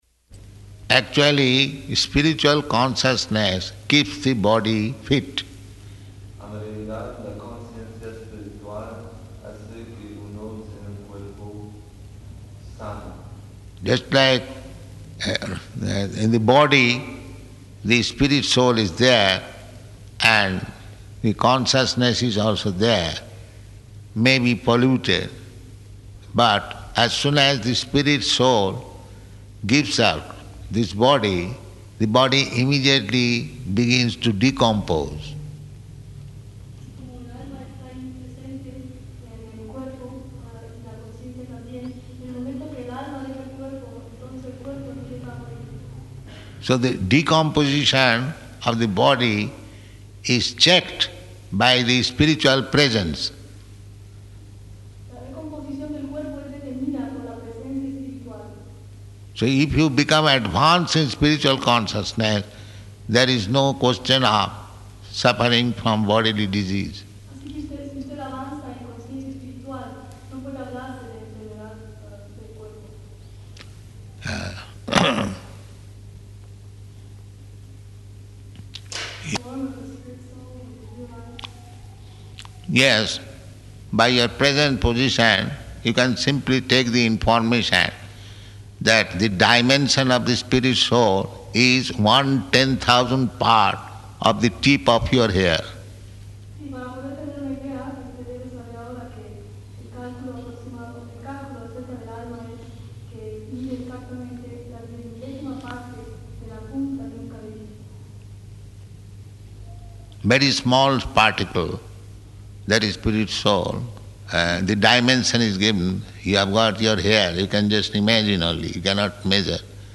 Questions and Answers
Questions and Answers --:-- --:-- Type: Other Dated: February 14th 1975 Location: Mexico City Audio file: 750214QA.MEX.mp3 [translated into Spanish throughout] Prabhupāda: Actually, spiritual consciousness keeps the body fit.